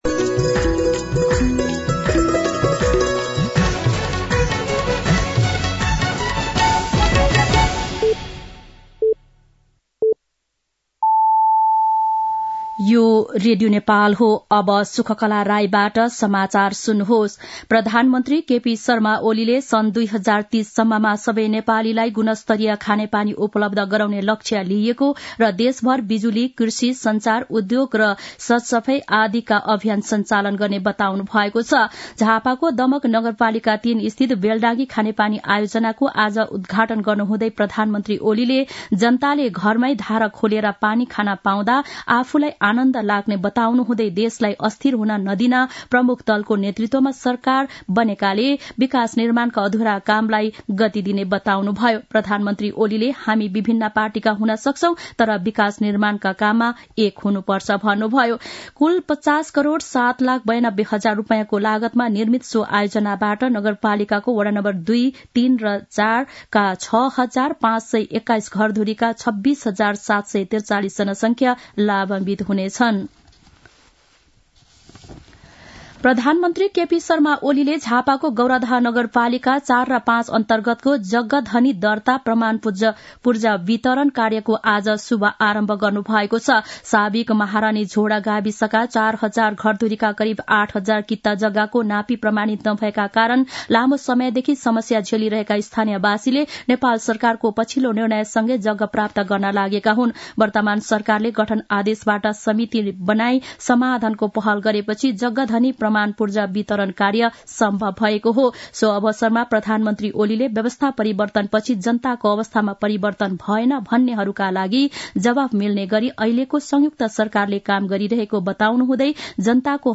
दिउँसो ४ बजेको नेपाली समाचार : २५ मंसिर , २०८१
4-pm-nepali-news-1-3.mp3